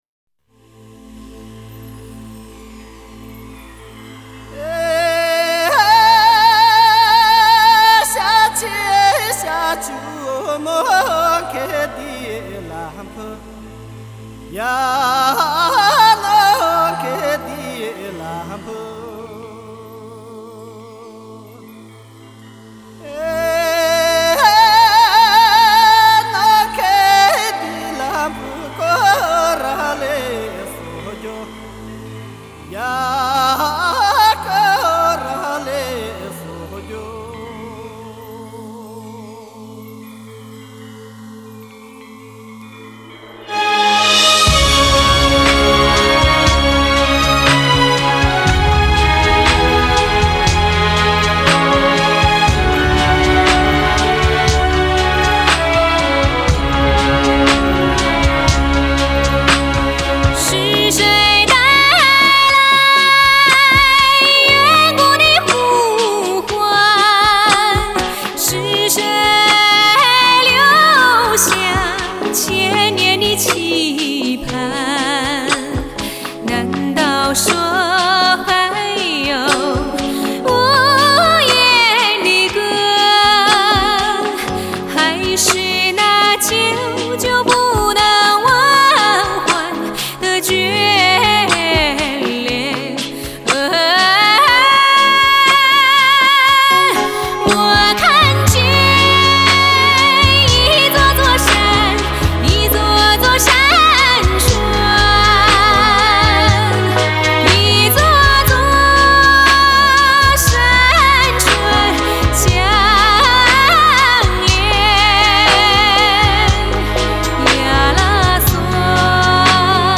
Жанр: Modern Traditional / Chinese pop / Tibetan folk